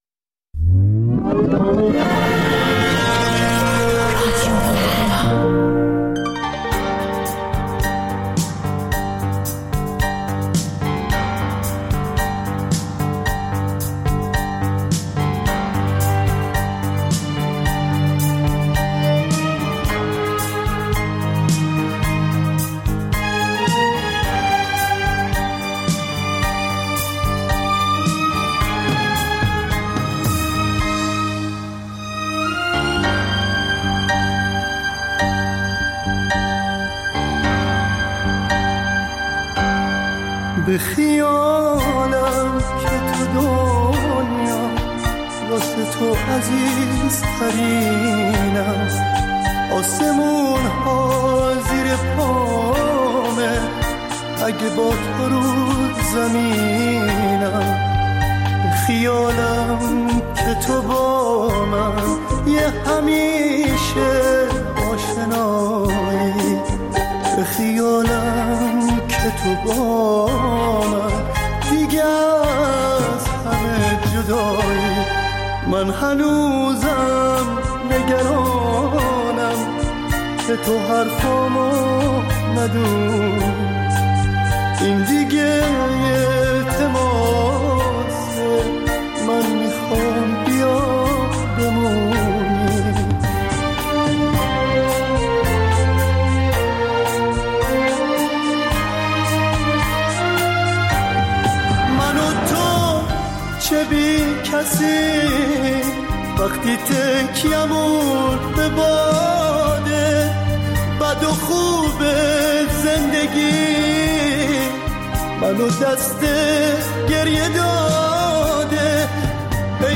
پخش زنده - پخش رادیویی